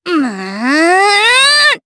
Miruru-Vox_Casting3_jp.wav